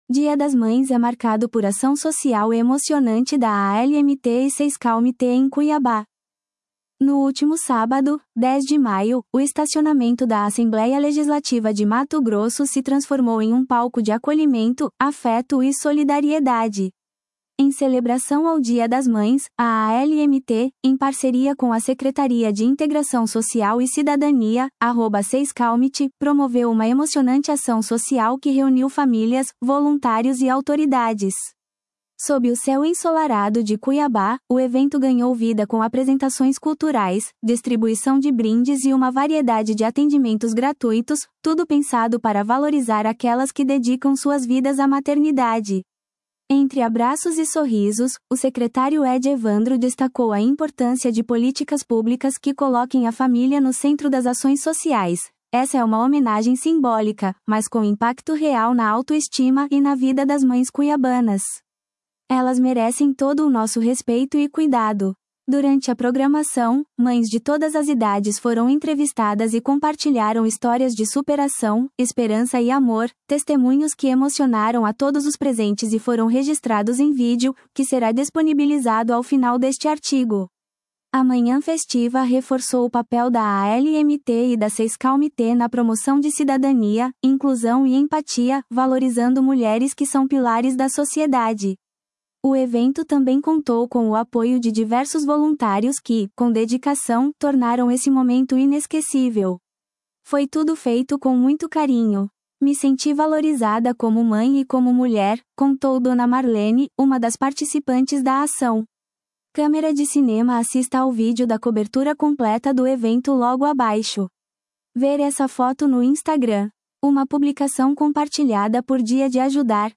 Durante a programação, mães de todas as idades foram entrevistadas e compartilharam histórias de superação, esperança e amor — testemunhos que emocionaram a todos os presentes e foram registrados em vídeo, que será disponibilizado ao final deste artigo.